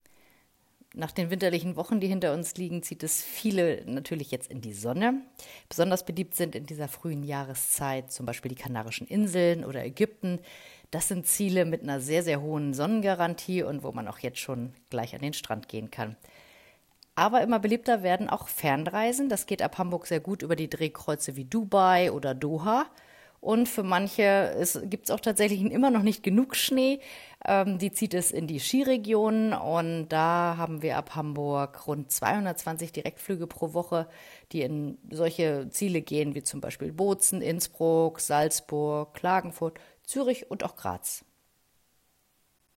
Audio-Statements